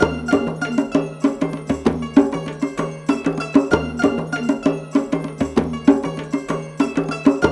Index of /90_sSampleCDs/Zero G - Ethnic/Partition F/ZITHERLOOPS2
ZITHALOOP8-L.wav